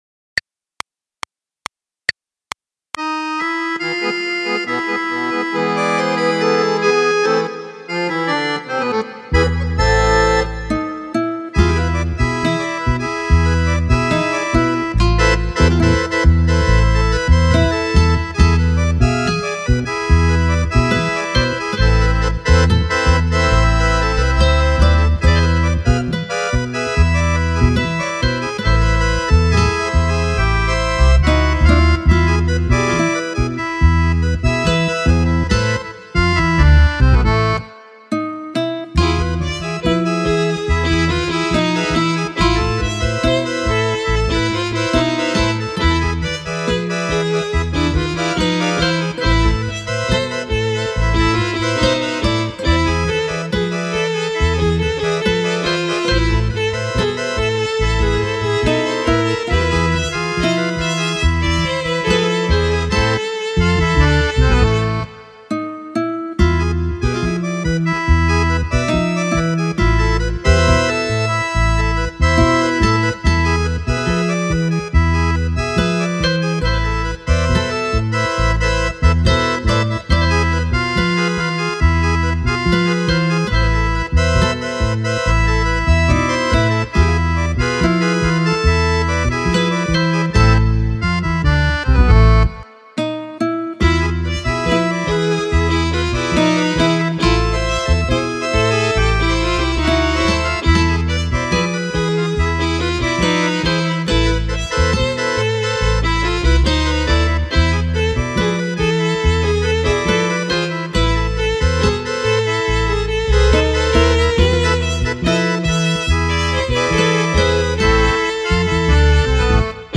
Arreglo instrumental y teclado